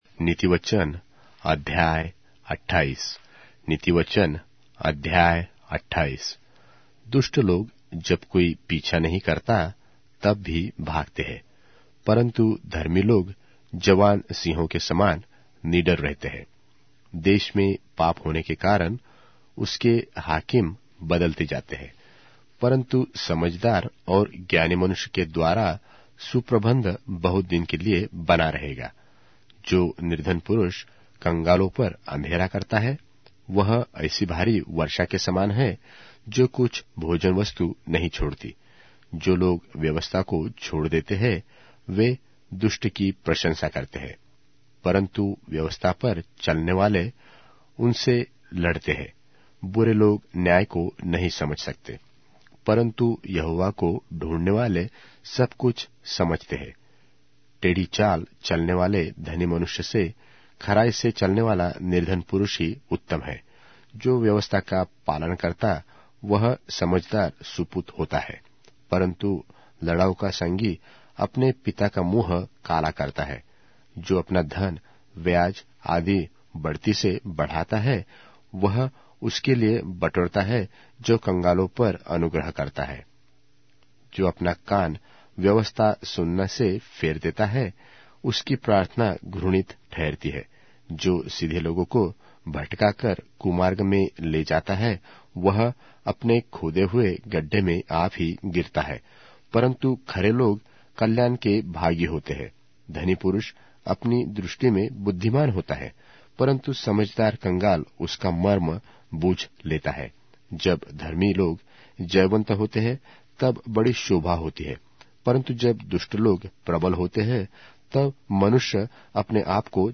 Hindi Audio Bible - Proverbs 24 in Gnttrp bible version